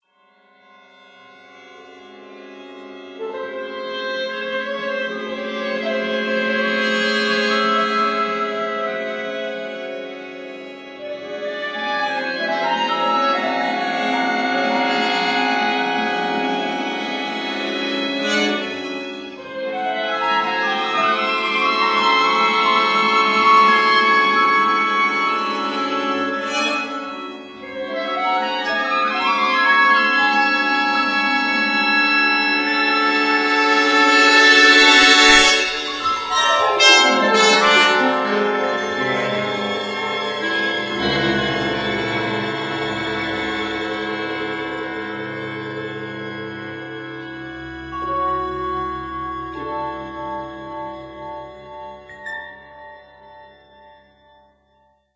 chamber ensemble